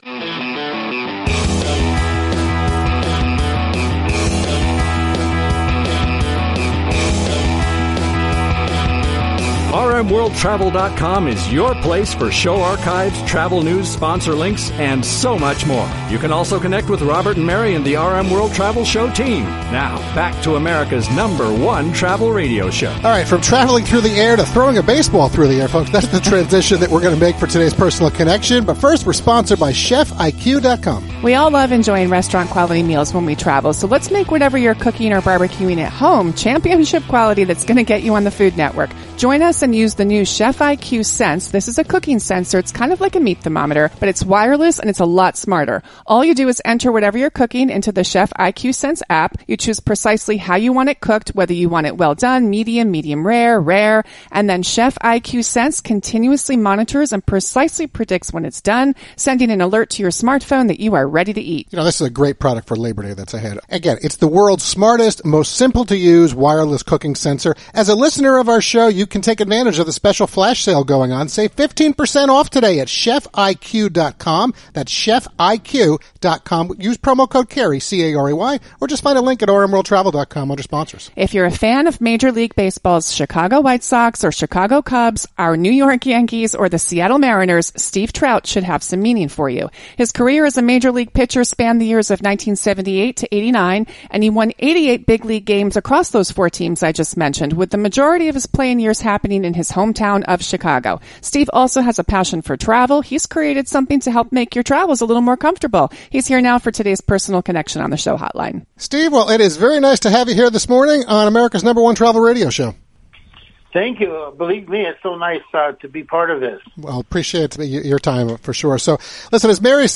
During the live national broadcast of America’s #1 Travel Radio Show on 9 August 2025
were joined by Steve via the Show Hotline to discuss The Pillow Mitt, his baseball career, love of travel and more.